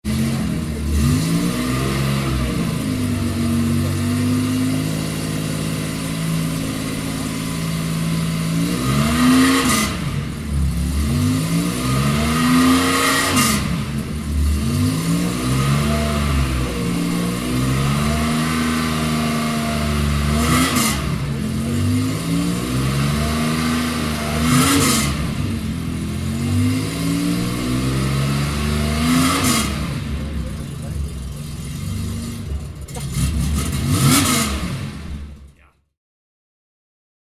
5th Porsche Sound Night 2015 - when racing legends wake up and scream (Event Articles)
PSN15__Porsche_911_MC_innen.mp3